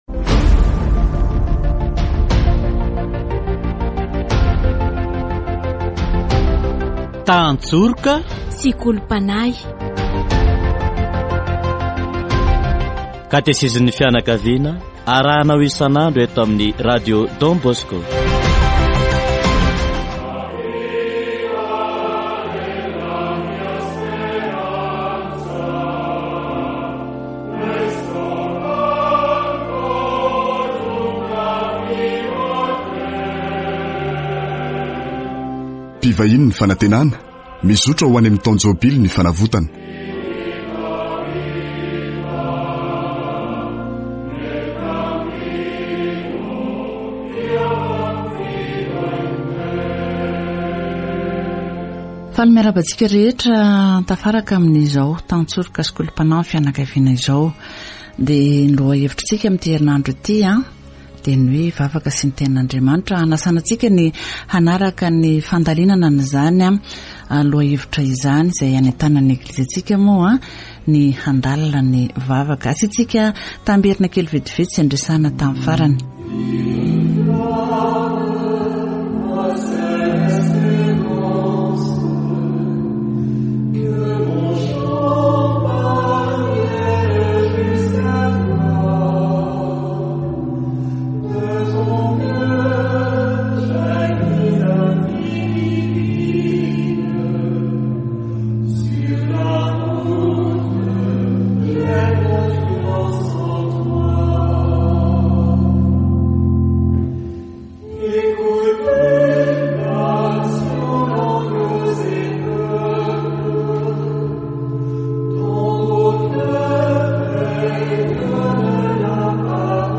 Category: Deepening faith